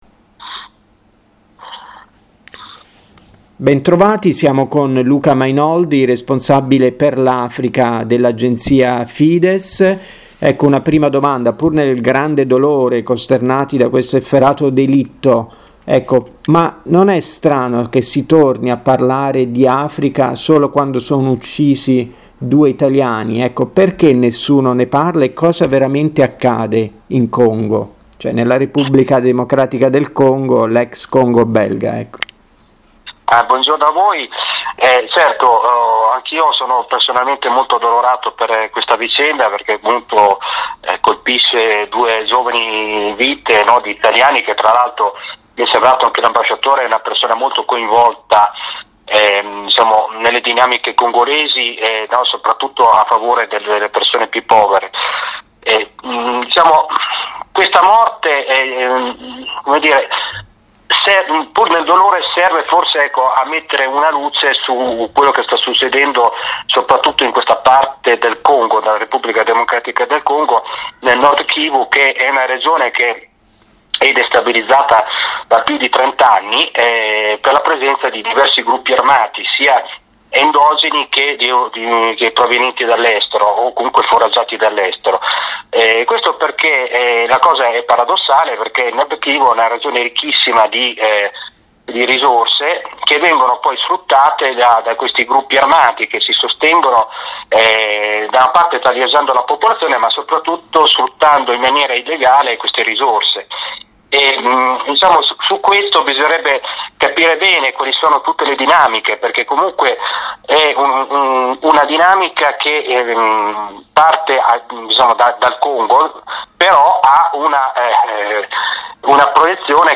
Mondo > Audioletture